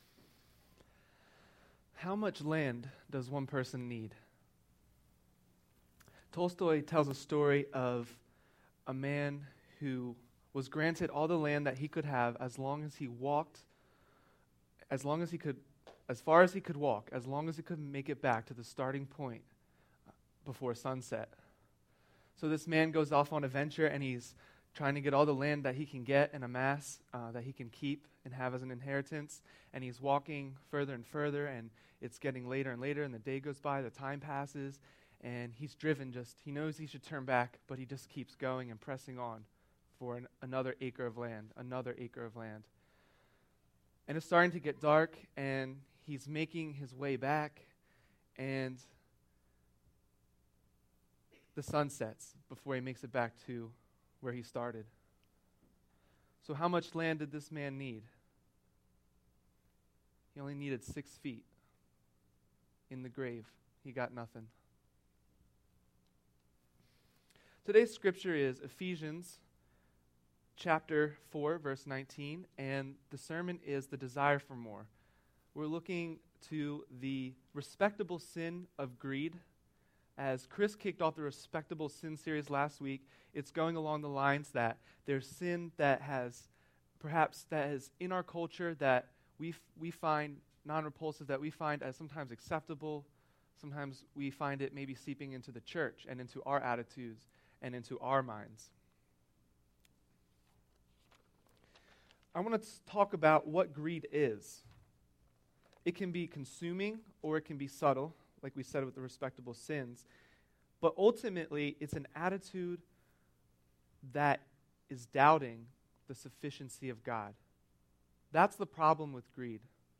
Finland Mennonite Church in Pennsburg, Pennsylvania, a Christ-centered Anabaptist Congregation.
Respectable Sins is a sermon series to remind us of what God says about things like greed, envy, idols, promiscuity and more.